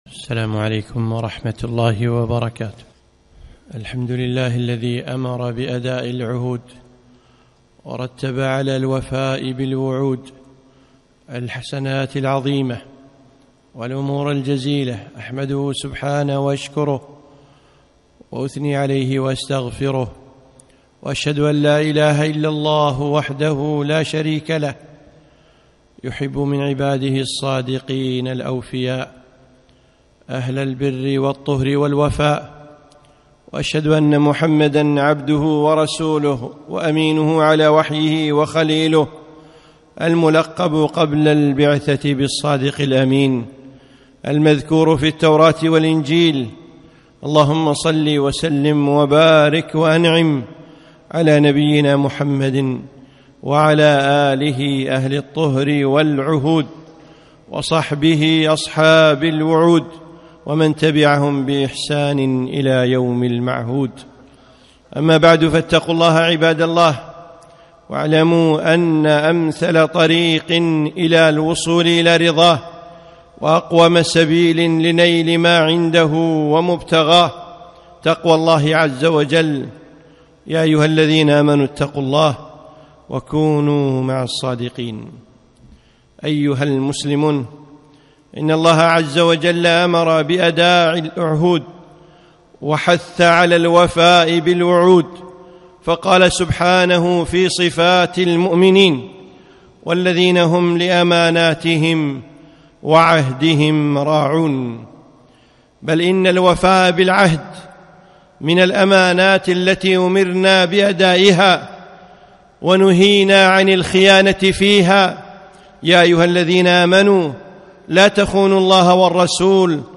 خطبة - العهد العهد